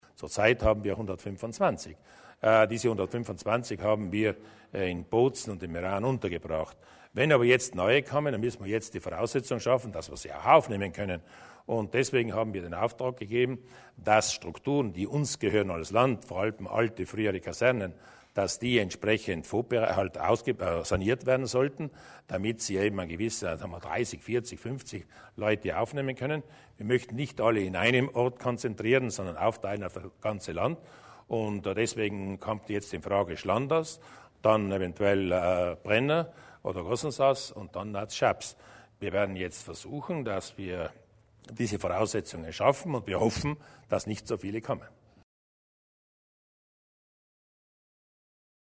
Landeshauptmann Durnwalder zur wahrscheinlichen Ankunft neuer Flüchtlinge